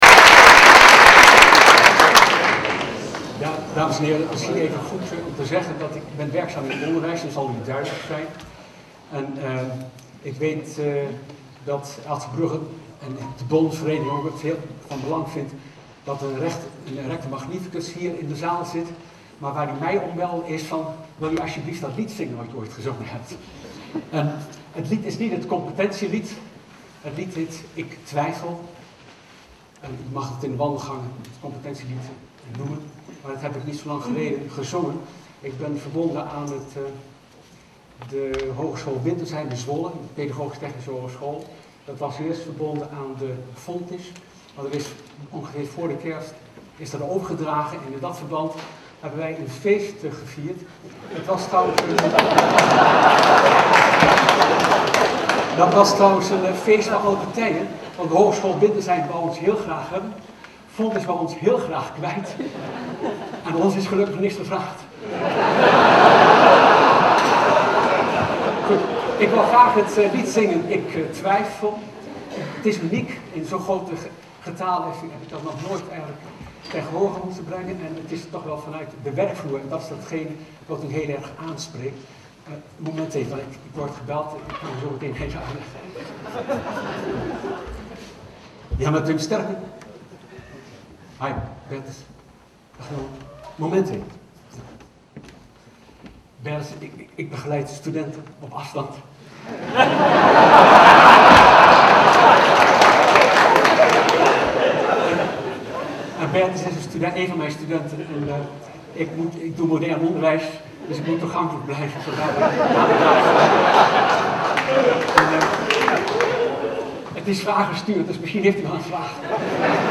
ALV en symposium 2007
Tijdens het symposium van 20 januari 2007, na de ALV, was een viertal sprekers aanwezig.